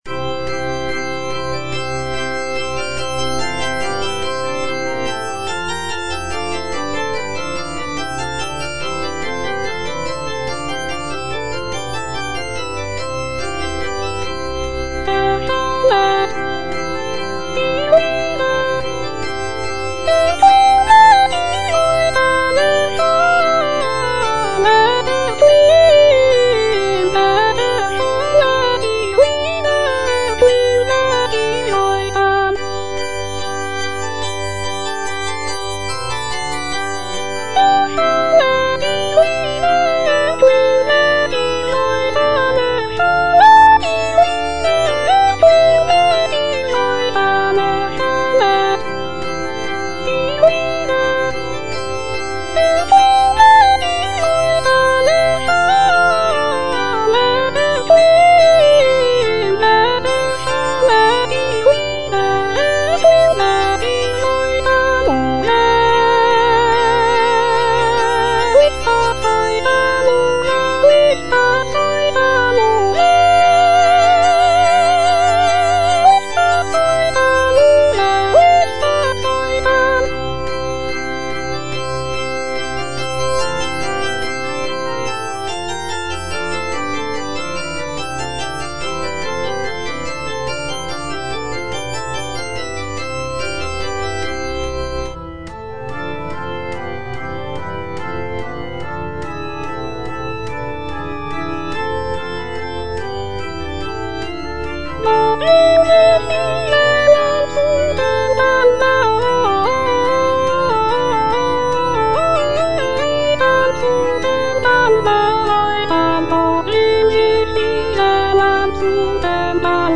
J.S. BACH - CANTATA "ERSCHALLET, IHR LIEDER" BWV172 (EDITION 2) Erschallet, ihr Lieder - Soprano (Voice with metronome) Ads stop: auto-stop
It features a jubilant opening chorus, expressive arias, and intricate chorales. The text celebrates the coming of the Holy Spirit and the birth of the Christian Church. The music is characterized by its lively rhythms, rich harmonies, and intricate counterpoint.